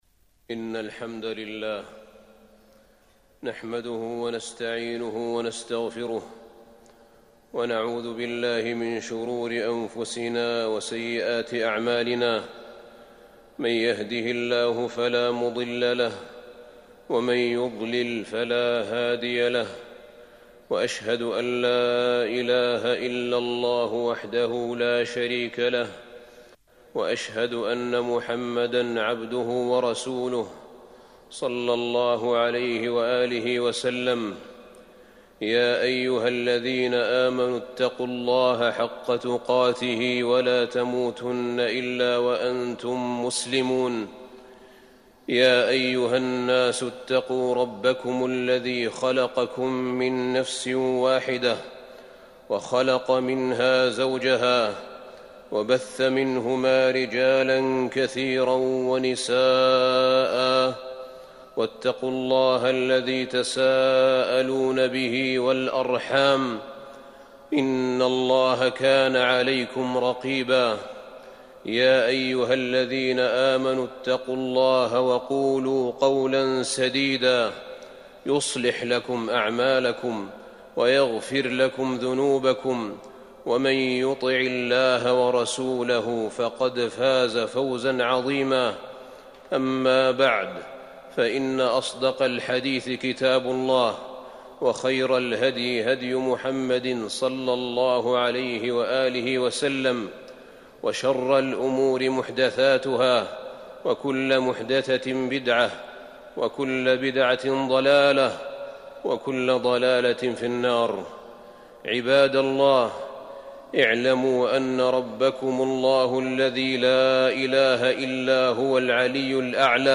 تاريخ النشر ١٥ رمضان ١٤٤١ هـ المكان: المسجد النبوي الشيخ: فضيلة الشيخ أحمد بن طالب بن حميد فضيلة الشيخ أحمد بن طالب بن حميد التعاجيب الأربع